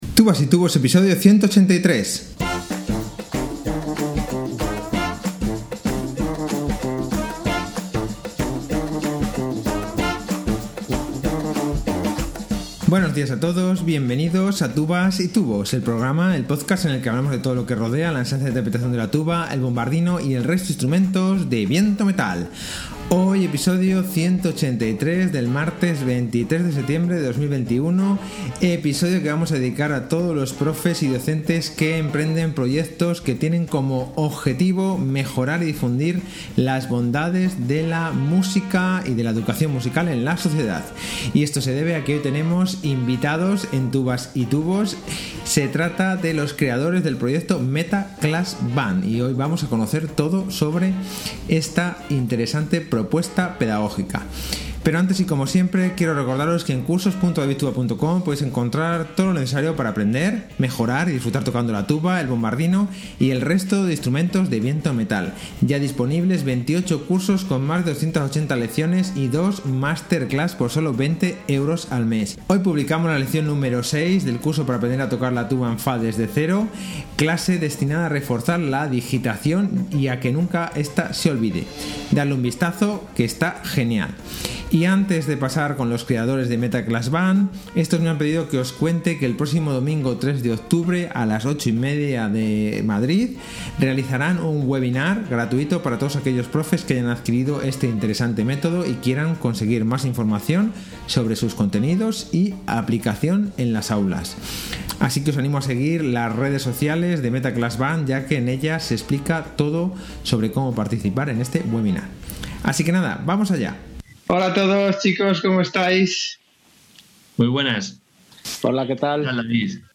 Esto se debe a que hoy tenemos invitados en tubas y tubos, se trata de los creadores del proyecto Meta ClassBand.